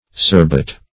Search Result for " surrebut" : The Collaborative International Dictionary of English v.0.48: Surrebut \Sur`re*but"\, v. i. [Pref. sur + rebut.] (Law) To reply, as a plaintiff to a defendant's rebutter.